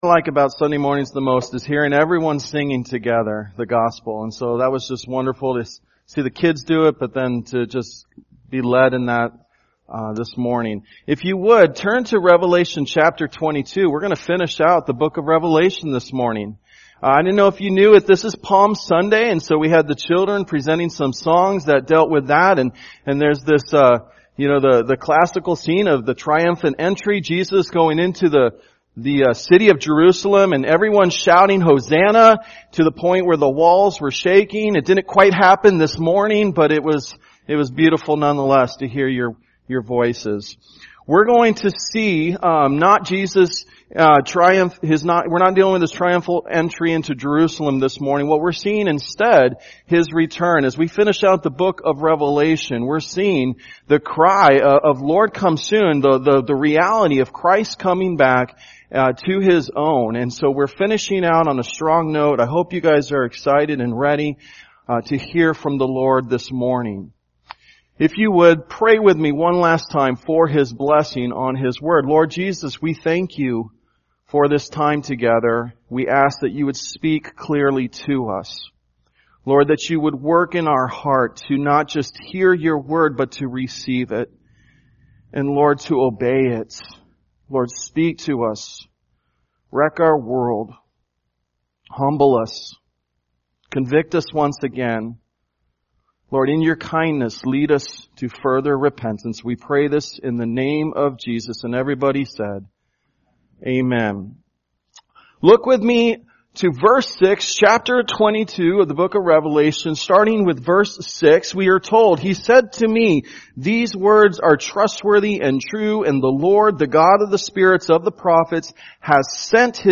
Sunday Worship
Tagged with Sunday Sermons Audio (MP3) 13 MB Previous Revelation 20:11-21:8: Rewards and Punishments Next Luke 24:1-12: He Is Risen